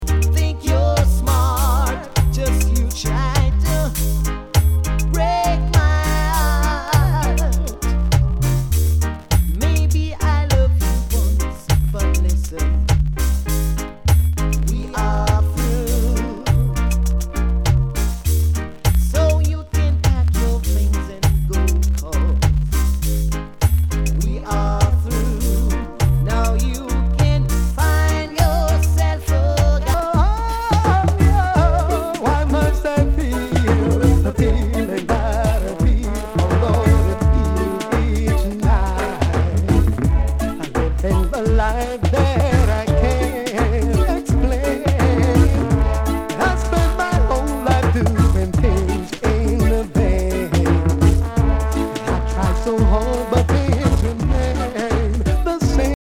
類別 雷鬼
ナイス！ダンスホール！
全体に大きくチリノイズが入ります